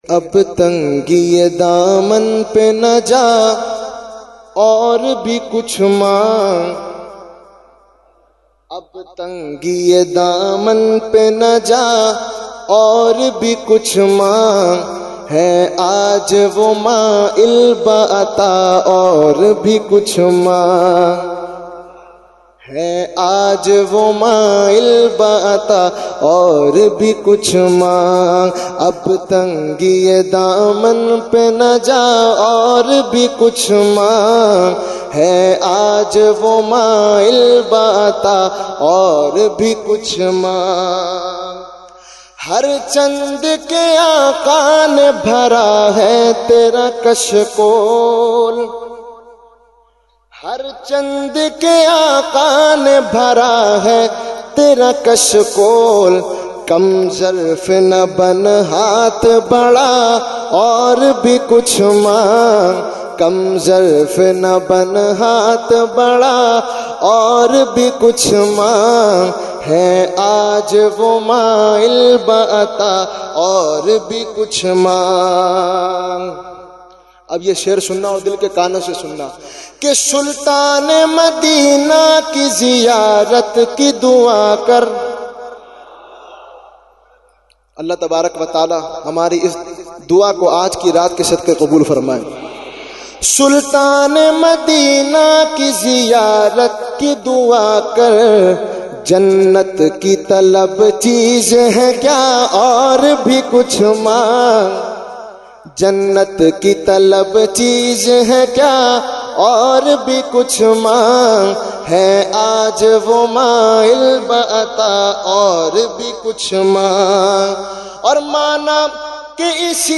Category : Naat | Language : UrduEvent : Jashne Subah Baharan 2019